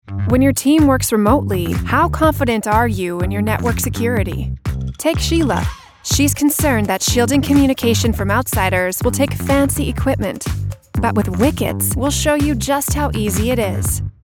Corporate Narration
• Corporate, Trustworthy, Authoritative - Cyber Security App